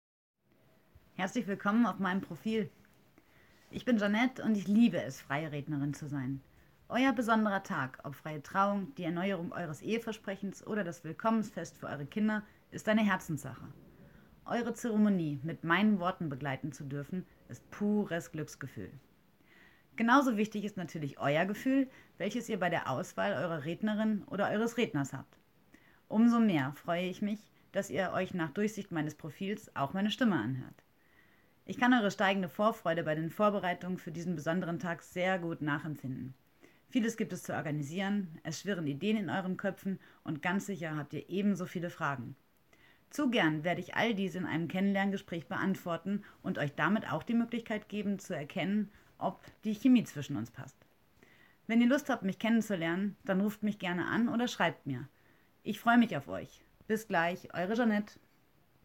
Stimmprobe